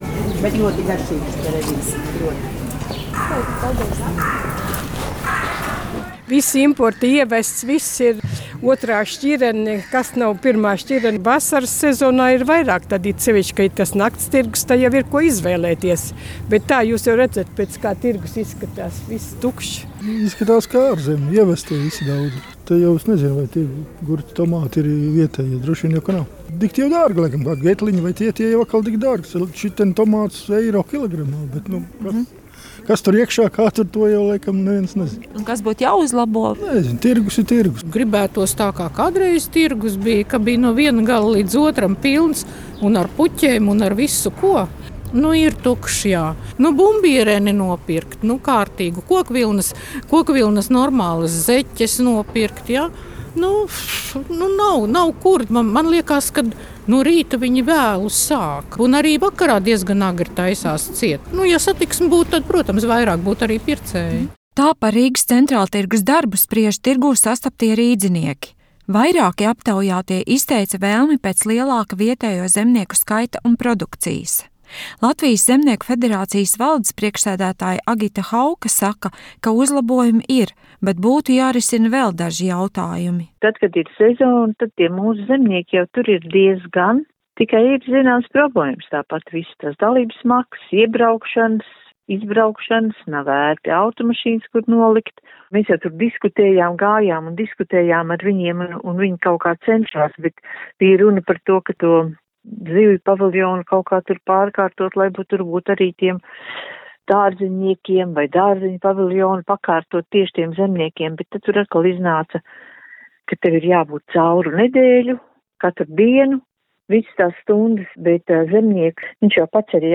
Tā par „Rīgas Centrāltirgus” darbu spriež tirgū sastaptie rīdzinieki.